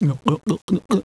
drink.wav